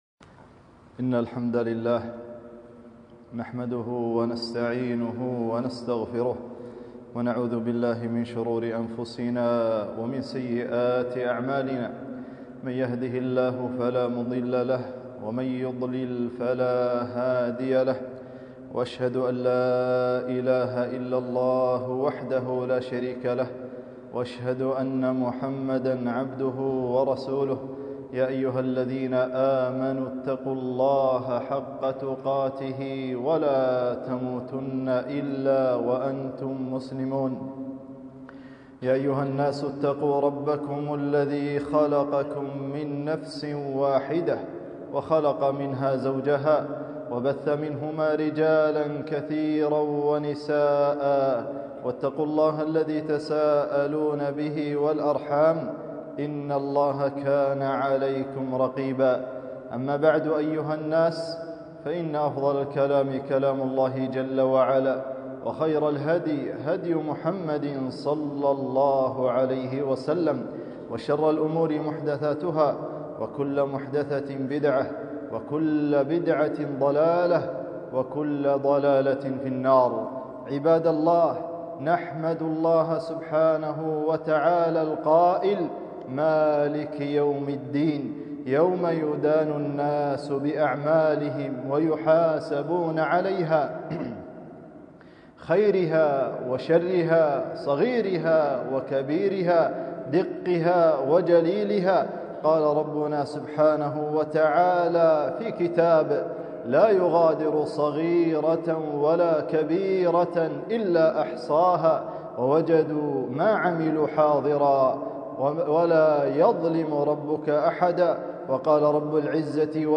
خطبة - تحريم الظلم وخطورته